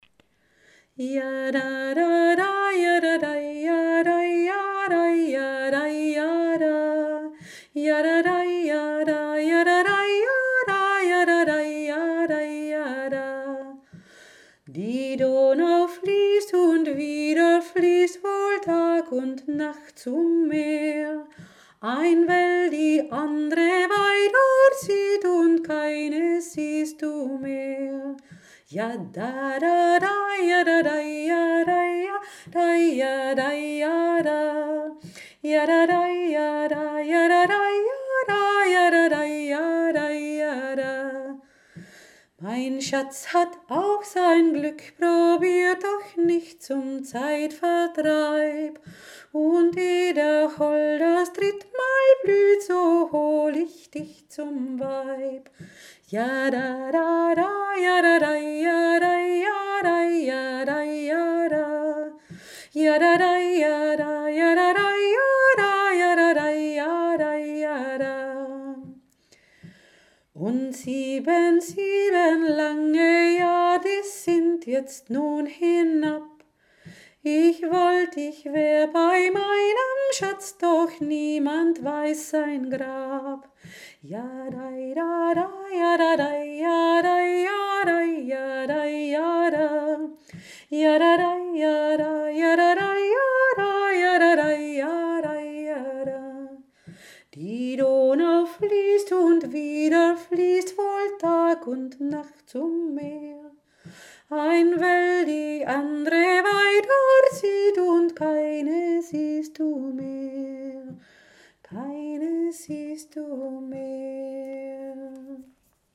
La canzone a due voci